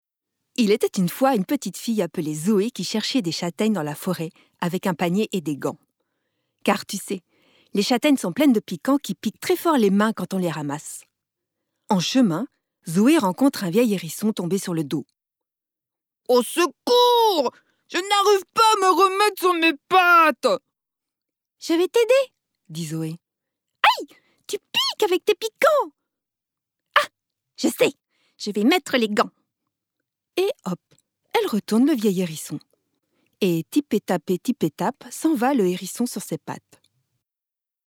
Conte
Voix off
5 - 53 ans - Mezzo-soprano